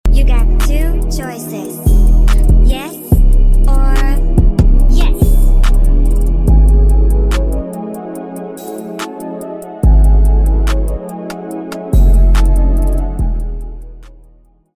Meow Meow Meow Meow Meow Sound Effects Free Download